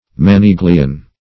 Search Result for " maniglion" : The Collaborative International Dictionary of English v.0.48: maniglion \ma*ni"glion\ (m[.a]*n[i^]l"y[u^]n), n. [It. maniglio, maniglia, bracelet, handle.